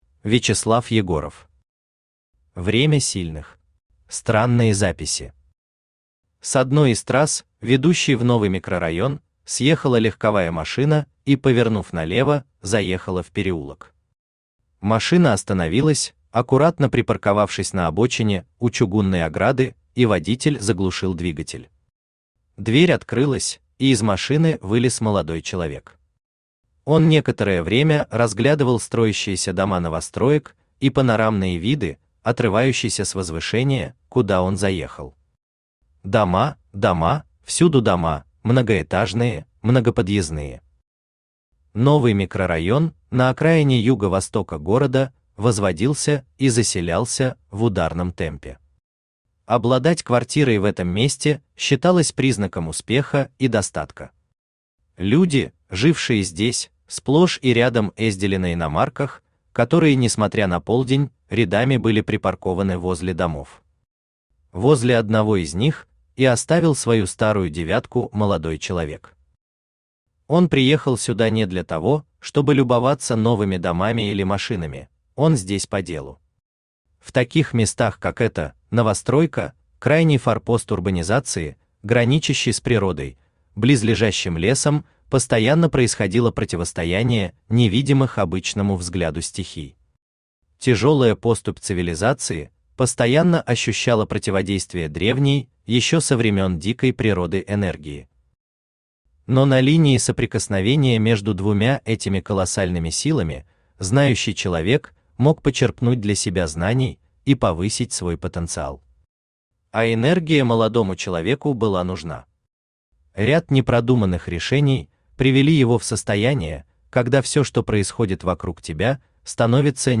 Аудиокнига Время сильных | Библиотека аудиокниг
Aудиокнига Время сильных Автор Вячеслав Анатольевич Егоров Читает аудиокнигу Авточтец ЛитРес.